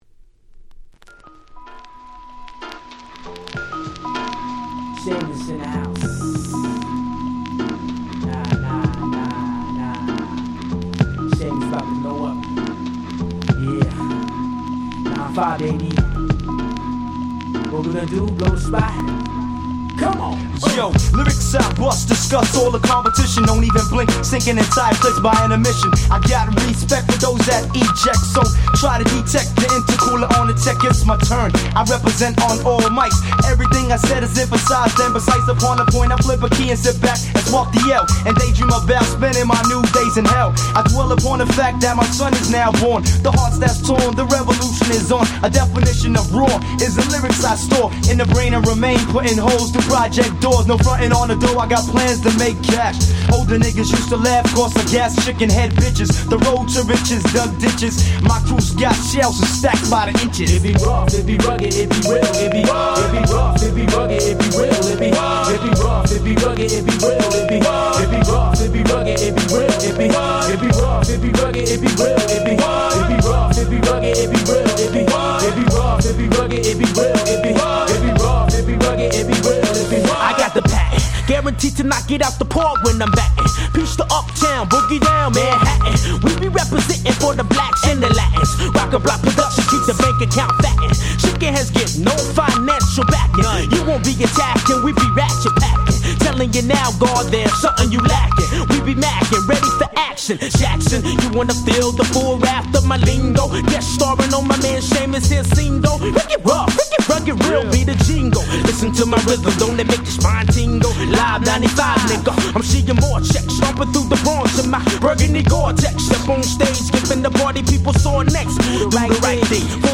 90's アンダーグラウンド アングラ　Boom Bap ブーンバップ